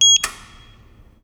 Garbage Beeper.WAV